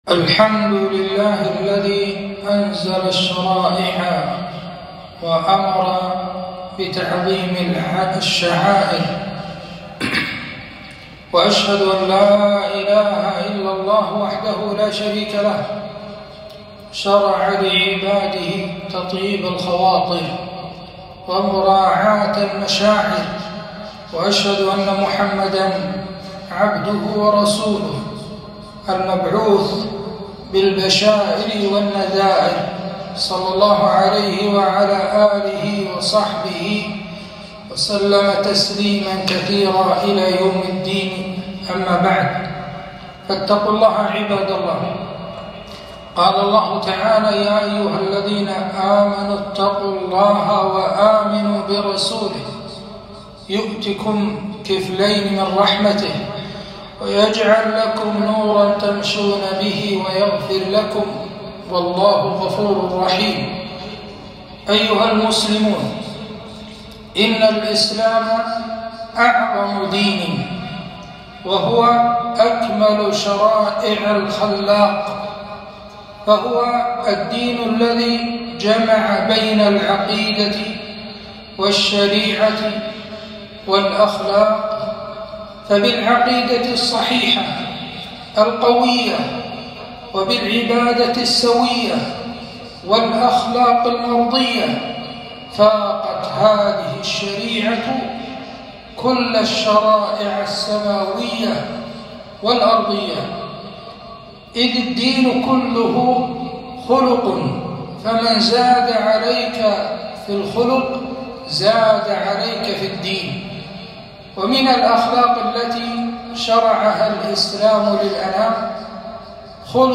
خطبة - جبر الخواطر